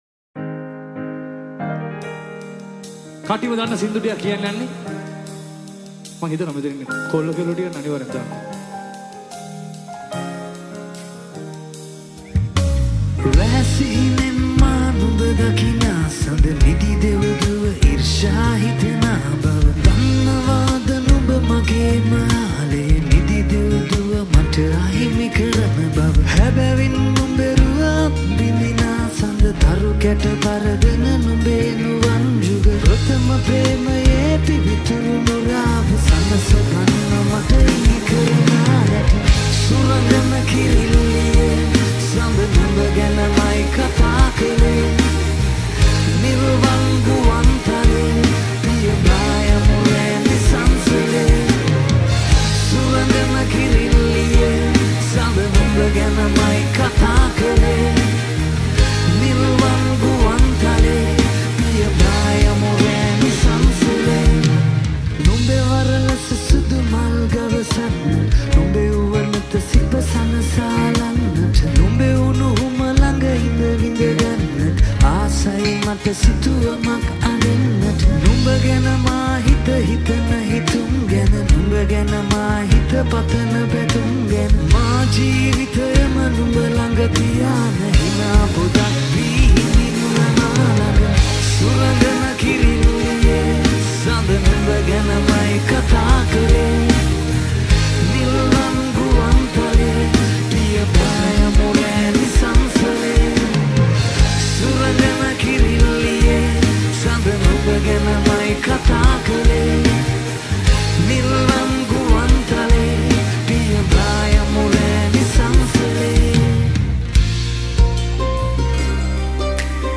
Live Music Rearranged